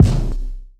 Stck_brk_kick_2.wav